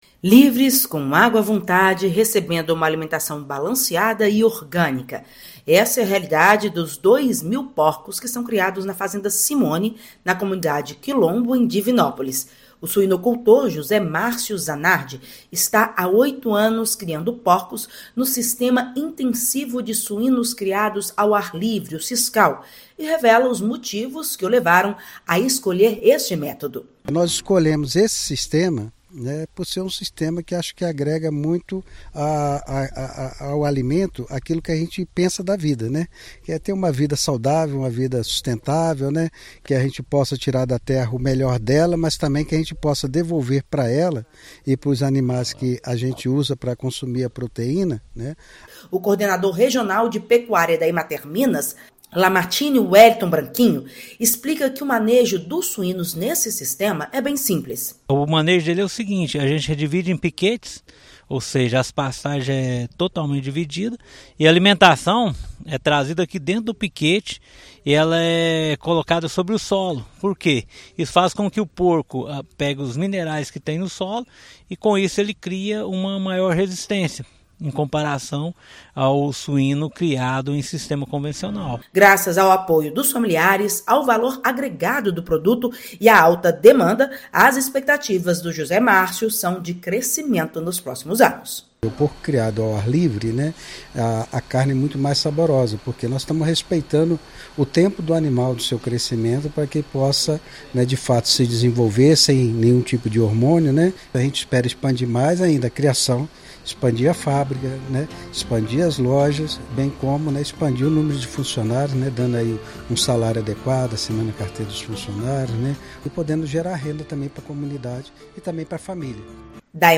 O método de criação dos animais, ao ar livre e com alimentação orgânica, faz com que eles se adaptem melhor às oscilações climáticas. Ouça matéria de rádio.